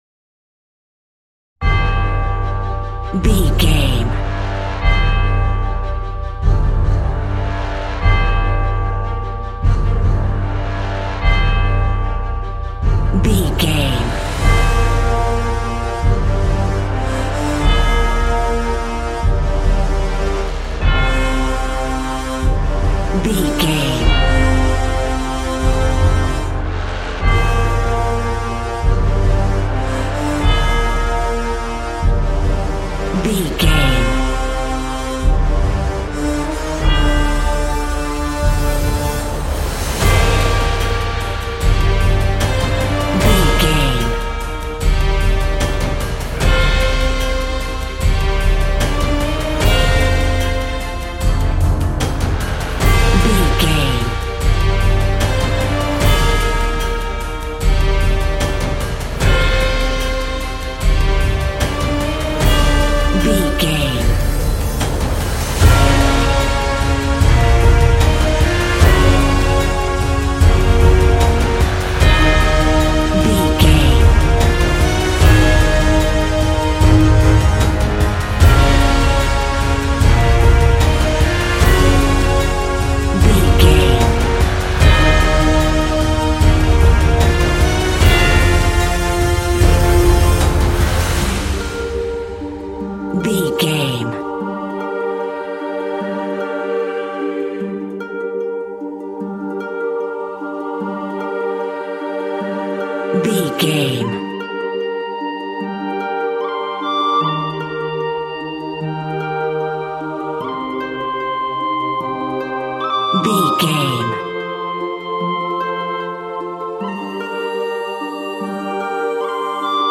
Epic / Action
In-crescendo
Dorian
Fast
brass
orchestral
choir
strings
drums
foreboding
ominous
aggressive
intense
cello
horns
trumpet